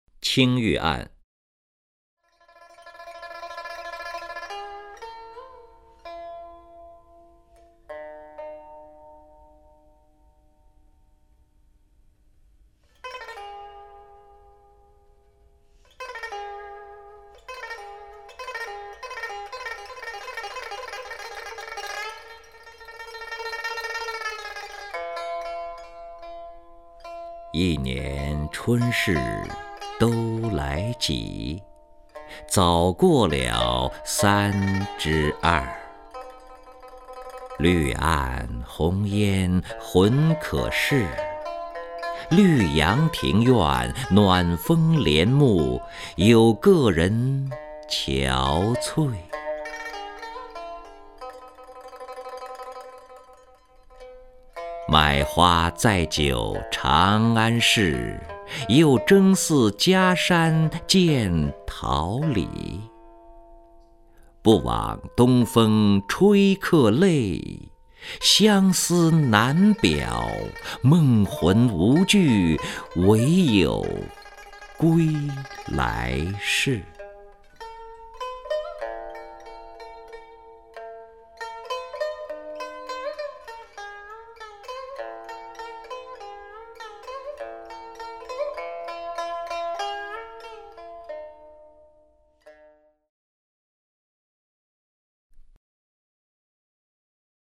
任志宏朗诵：《青玉案·一年春事都来几》(（北宋）欧阳修)
名家朗诵欣赏 任志宏 目录